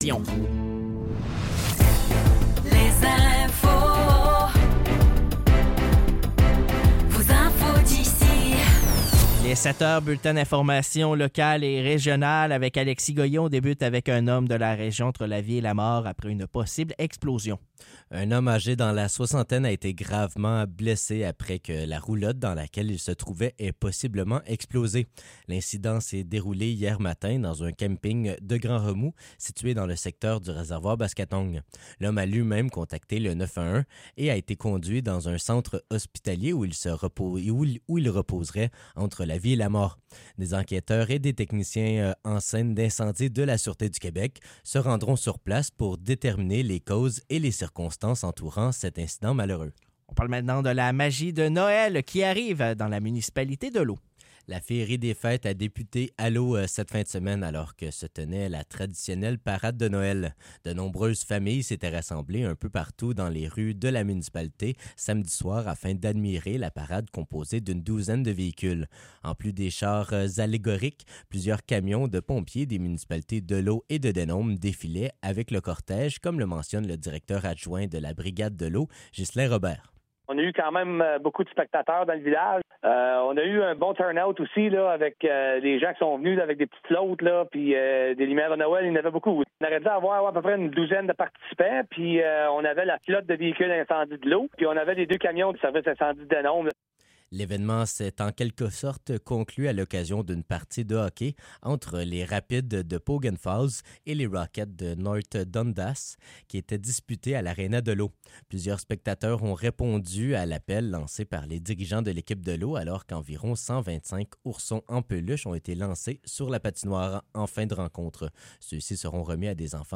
Nouvelles locales - 2 décembre 2024 - 7 h